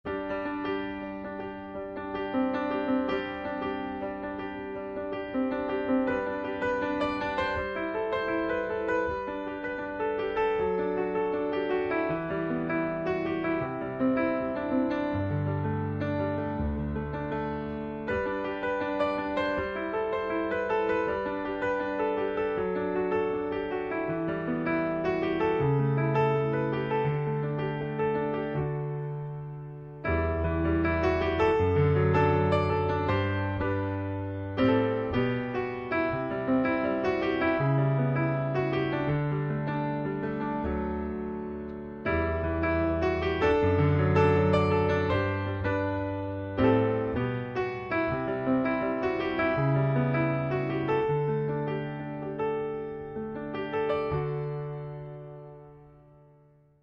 voici déjà le son originale, un piano à queu pris avec deux micros; la prise est déjà très correct et le son est très bon pas trop mate pas trop brillant (sonorité intimiste et presque parfait)
Les micros (que je pense sont 2) sont trop panés, on a à l'écoute avec un casque trop de choses à gauche et trop de choses à droite, bref le centre est quasi inexistant.